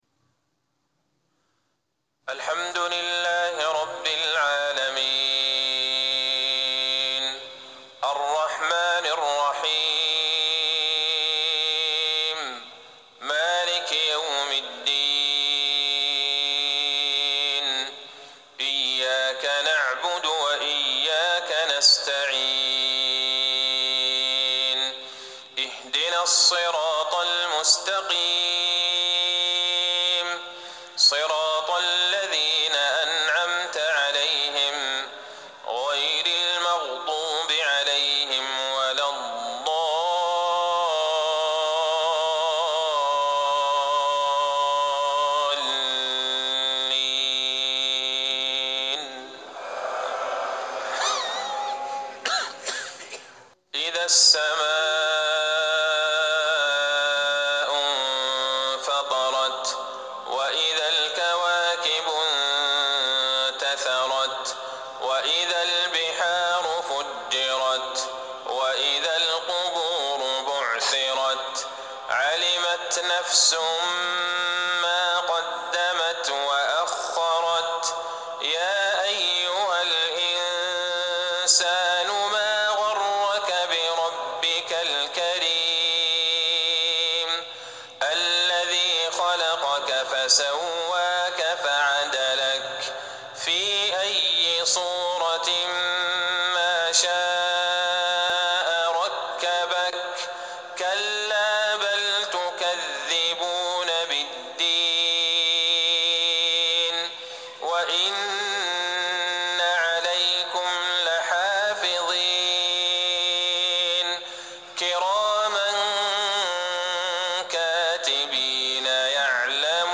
صلاة العشاء 2-5-1440هـ سورتي الإنفطار و البلد | Isha 8-1-2019 prayer from surah Alinfitar and al-Balad > 1440 🕌 > الفروض - تلاوات الحرمين